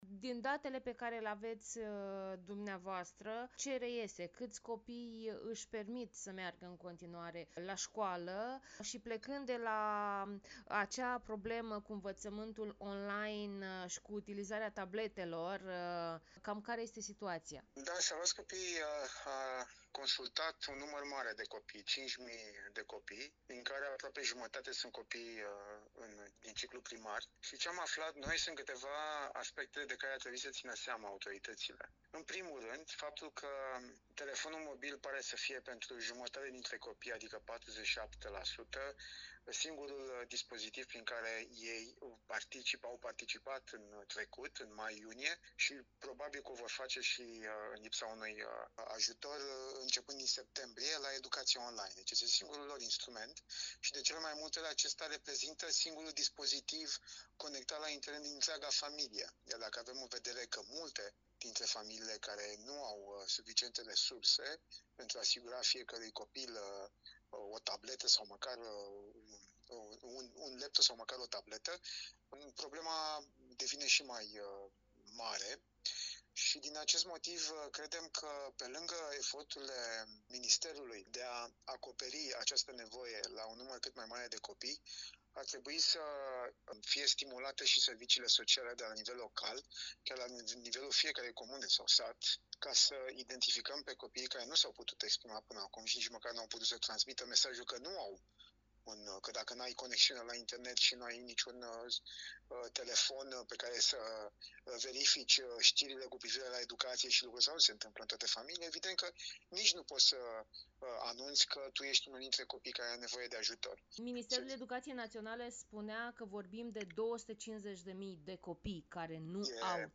(INTERVIU) Mii de copii nu au de pe ce să învețe